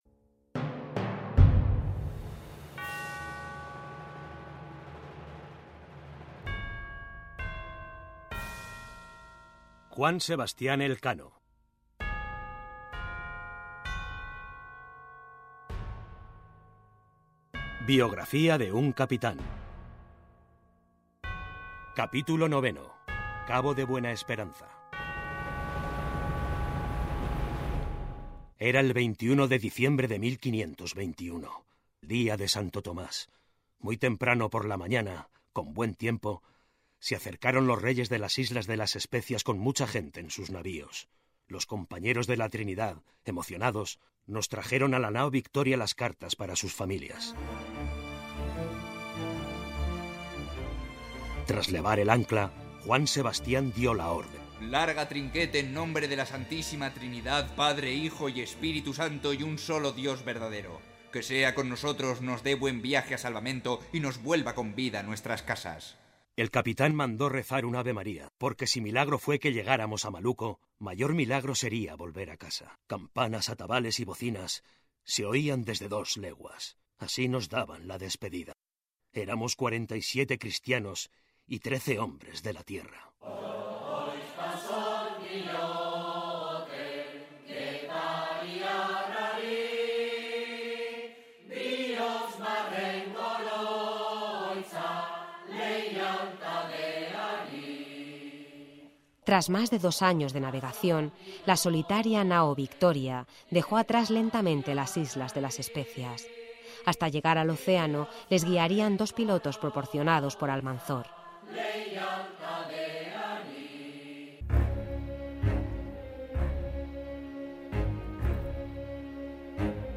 Audiolibro: Elkano biografía de un cápitan capítulo 9